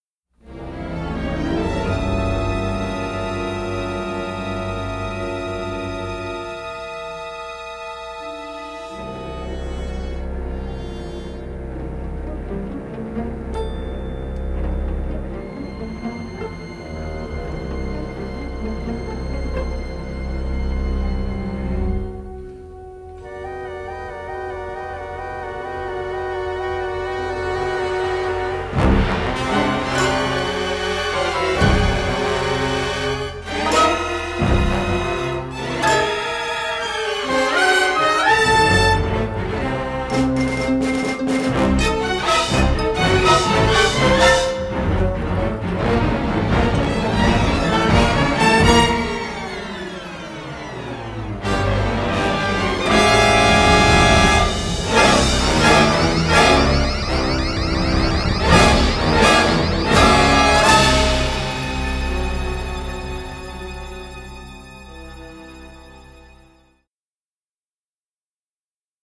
Dynamic orchestral score